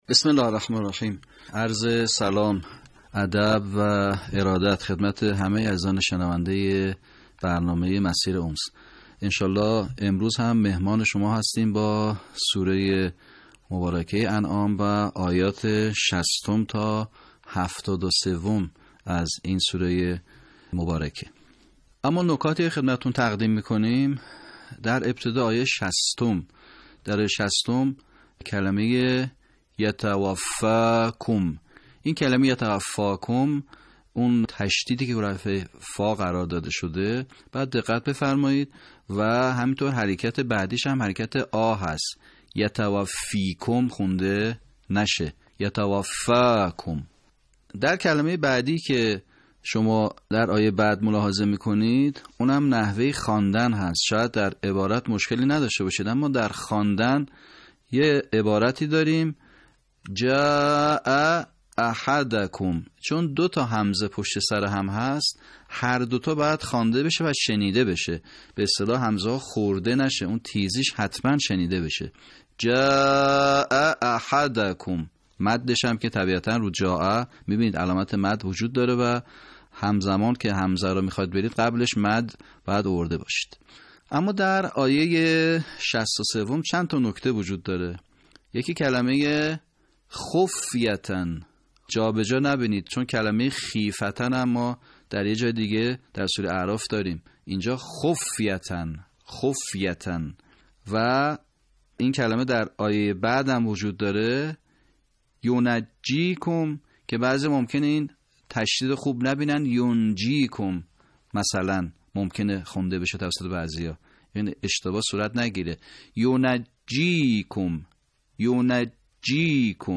صوت | آموزش صحیح‌خوانی آیات ۶۰ تا ۷۳ سوره انعام